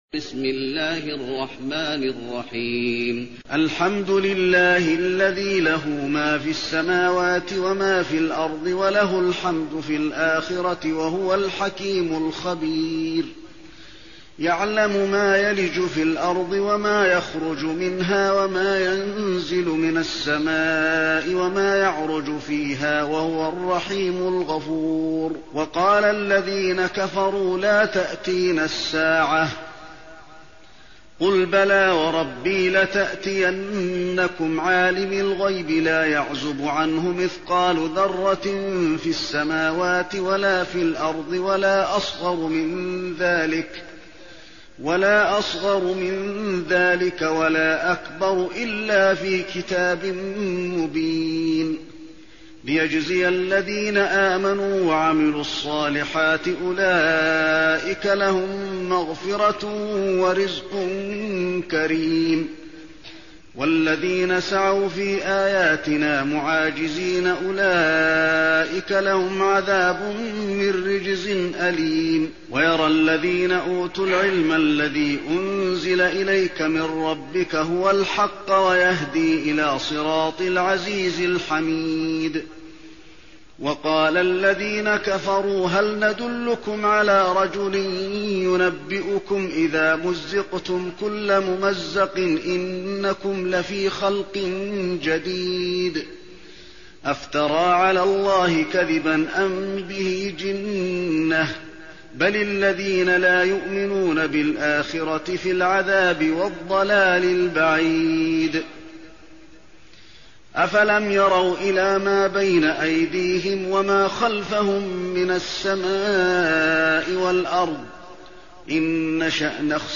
المكان: المسجد النبوي سبأ